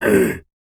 Male_Grunt_Hit_18.wav